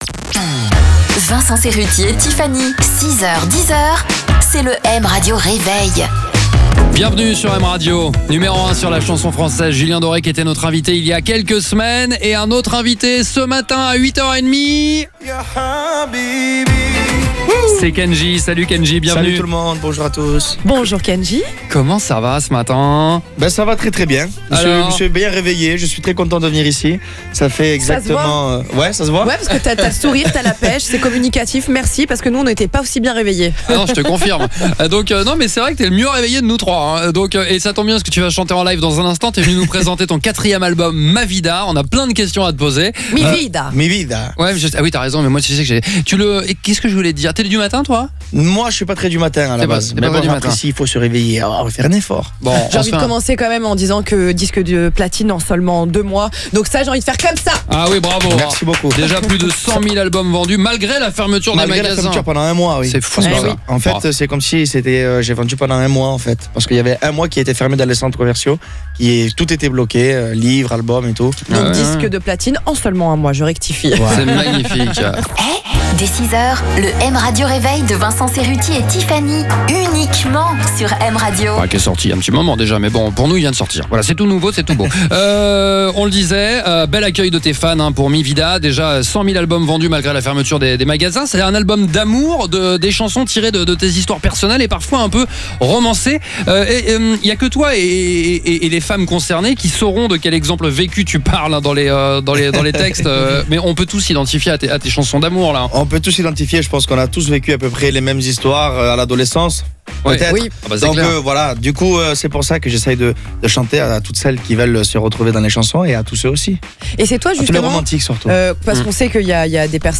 Et il a interprété 2 titres en live !